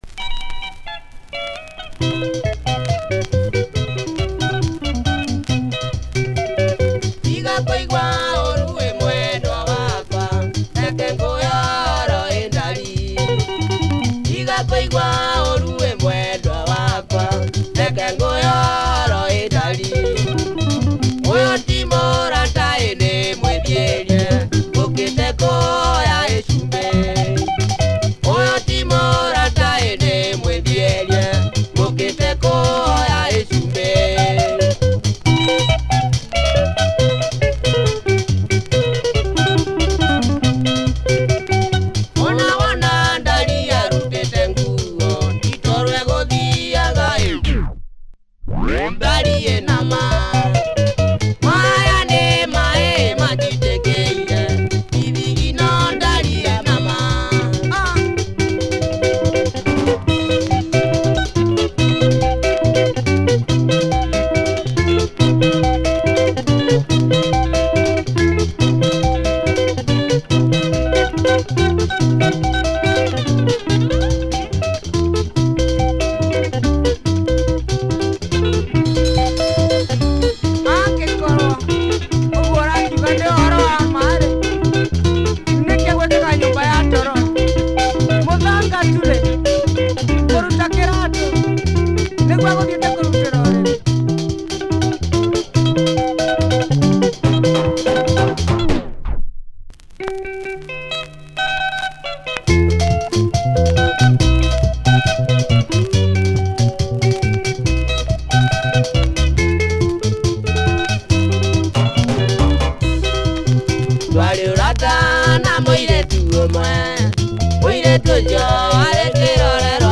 Typical Kikuyu benga